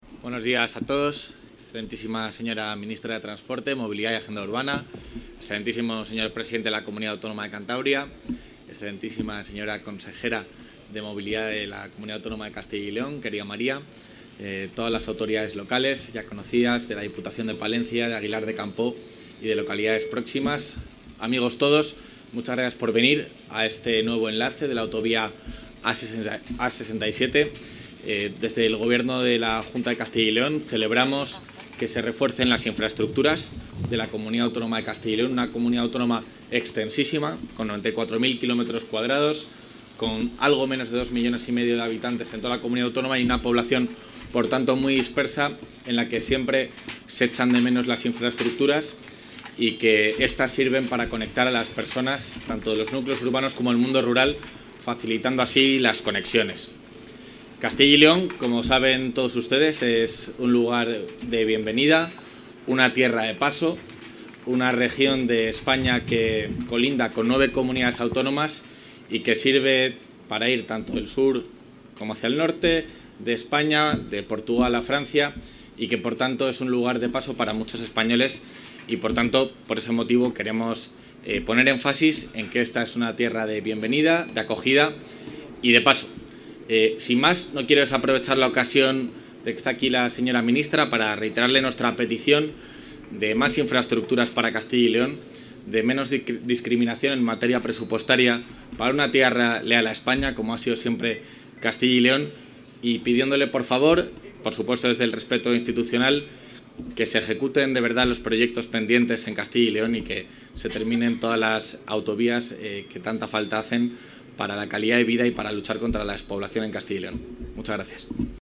Intervención del vicepresidente de la Junta.
El vicepresidente de la Junta de Castilla y León así lo ha reivindicado durante la puesta en servicio del nuevo enlace a la A-67 en la localidad palentina de Quintanilla de las Torres